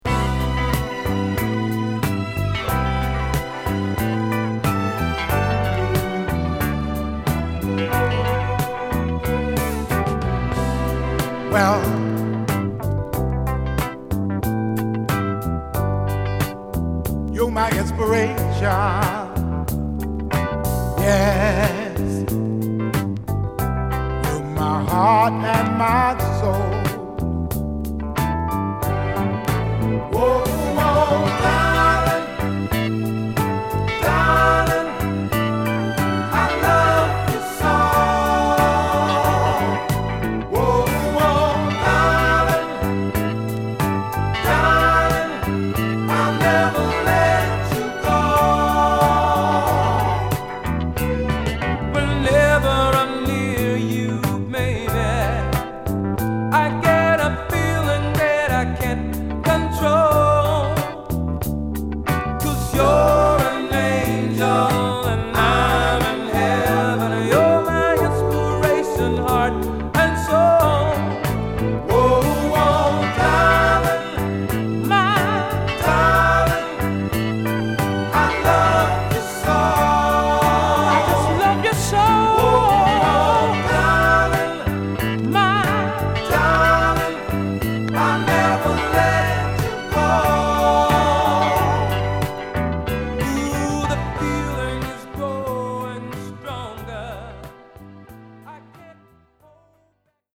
グルーヴィーなベースに引っ張られるゆったりとメロウなトラックに、切な気なコーラス＆ハーモニーを乗せるA
アーバンな雰囲気も持ったイイ曲です。